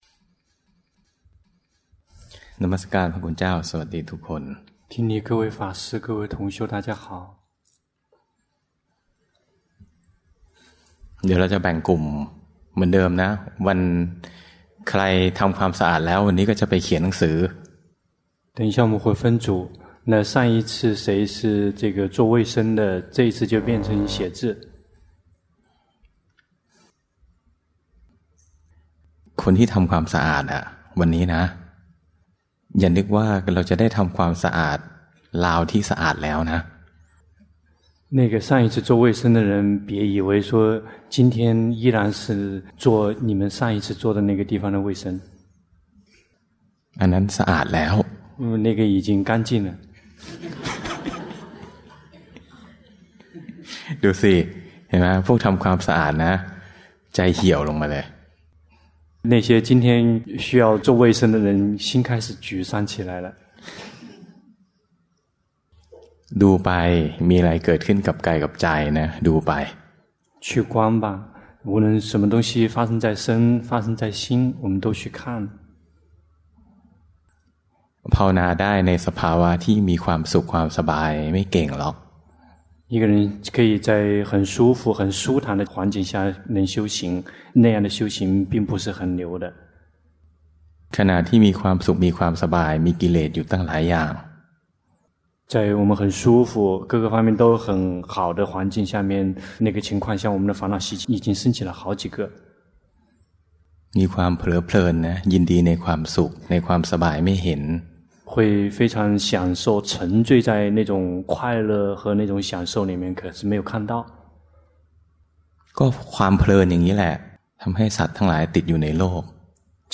第十二屆泰國四念處禪修課程 課程現場翻譯